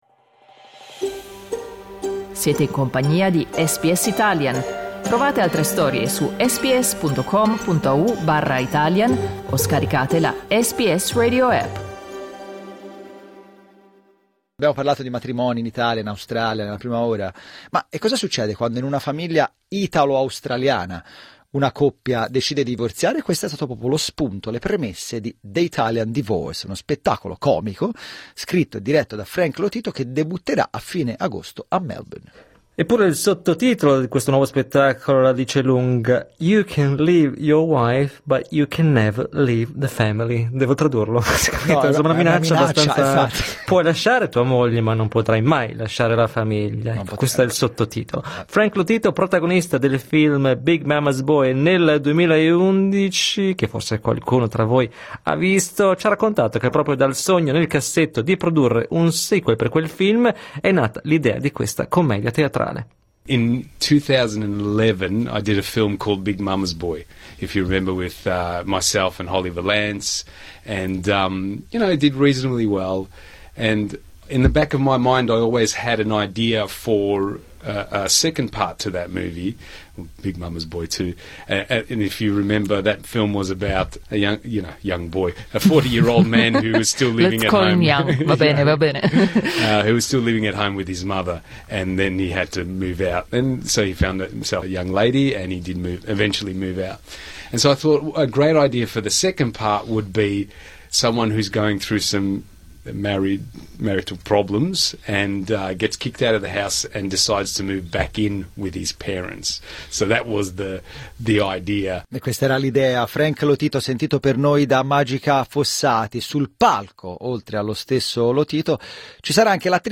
Clicca sul tasto "play" in alto per ascoltare un servizio in italiano sullo spettacolo Per maggiori informazioni sui biglietti per le date dello spettacolo The Italian Divorce al Williamstown Italian Social Club (il 29, il 30 agosto e ora anche il 31 agosto), visitate trybooking.